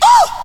VOX SHORTS-1 0016.wav